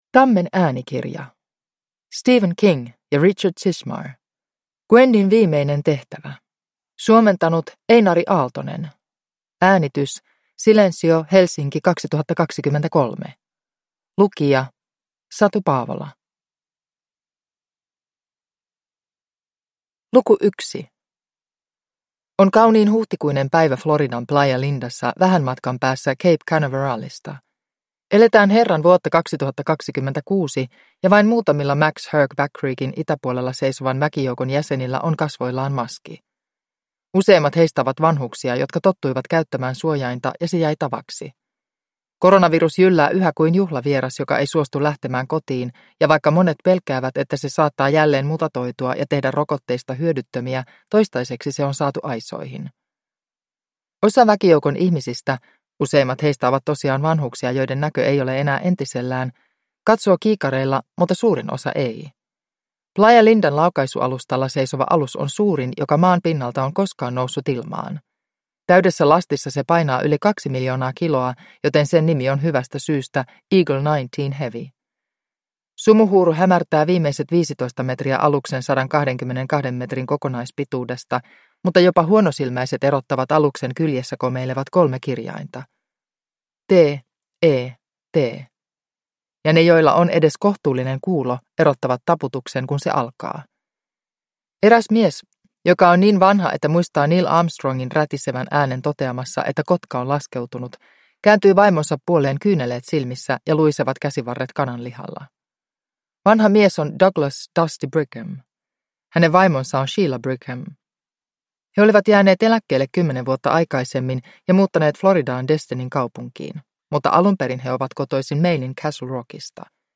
Gwendyn viimeinen tehtävä – Ljudbok – Laddas ner